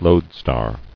[load·star]